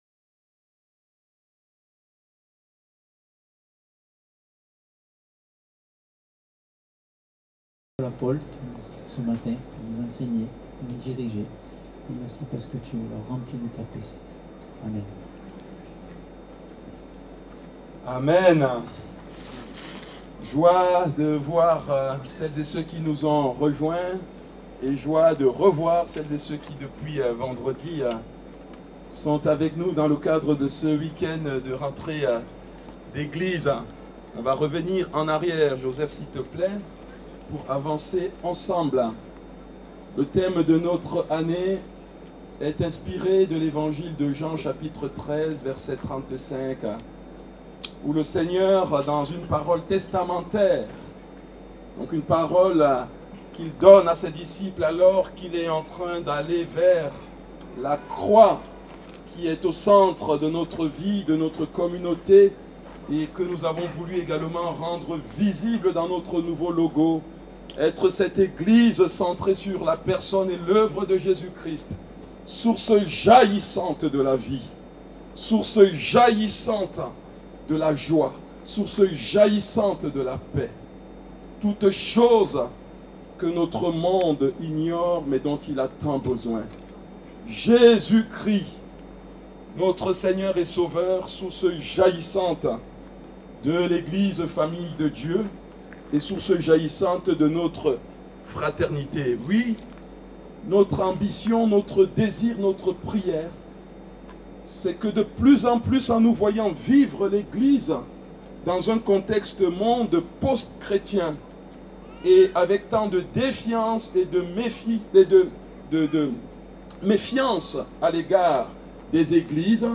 Culte de rentrée, prédication